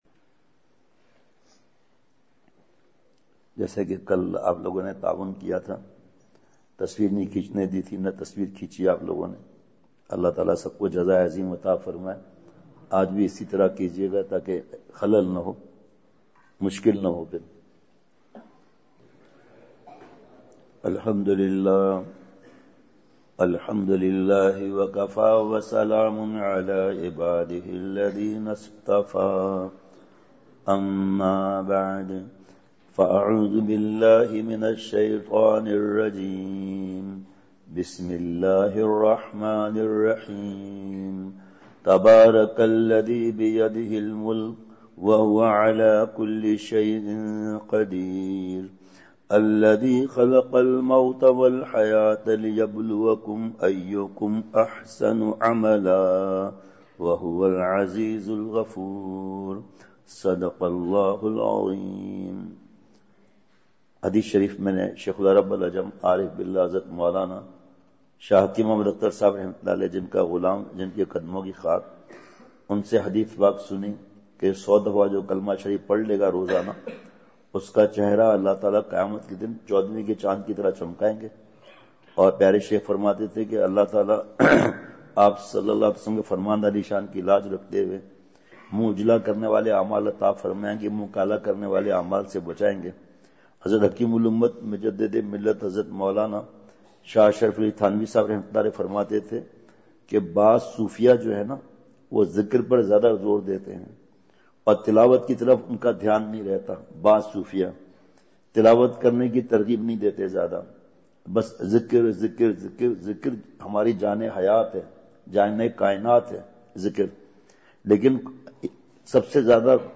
*بمقام:۔جامعہ دارالقرآن مسلم ٹاون فیصل آباد*
*نمبر(15):بیان*